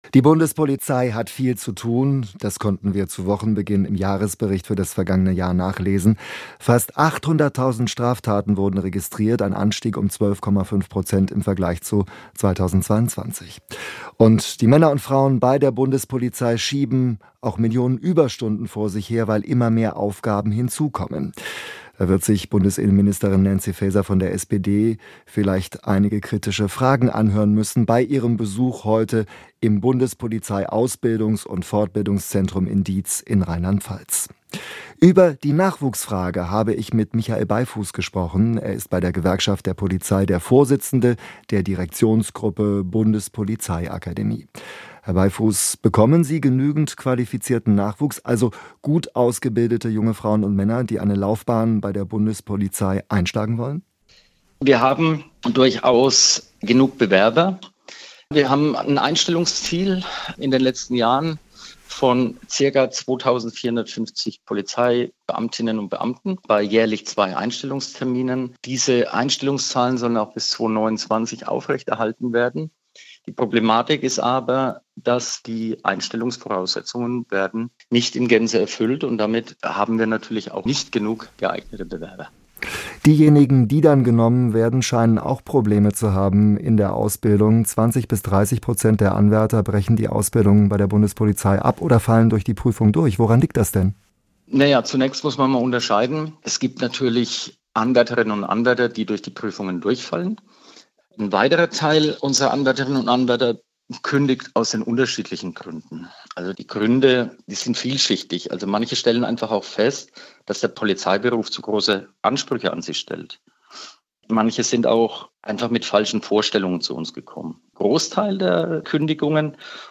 3. Radio